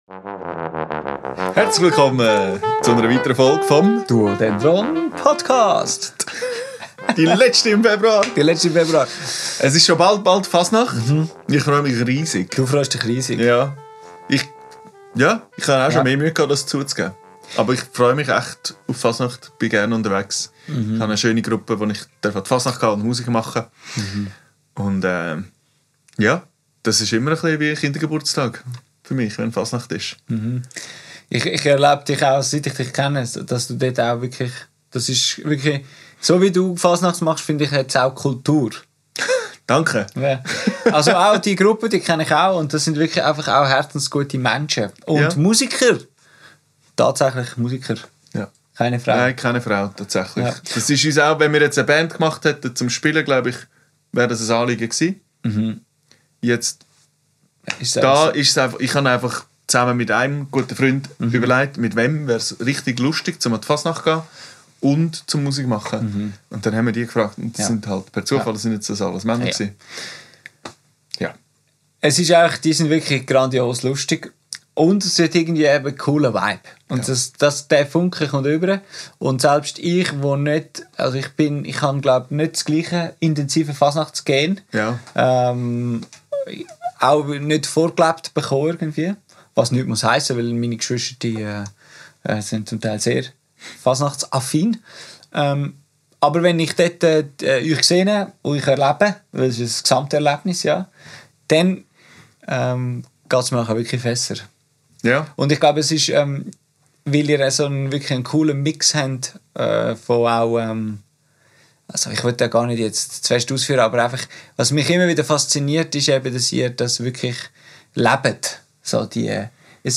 Eine etwas zu nüchterne Betrachtung dieses Brauches, seinen Inhalten und dem eigenen Bezug dazu. Aufgenommen am 29.01.2025 im Atelier